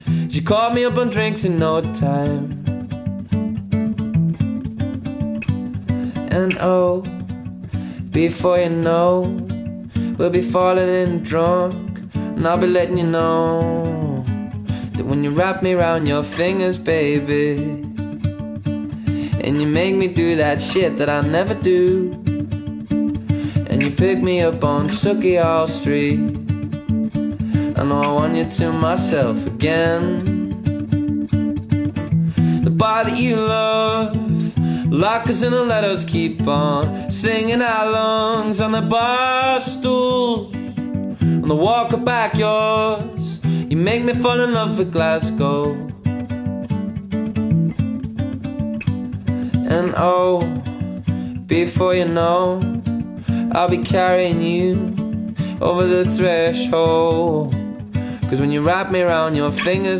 live at Glasgow green July 2025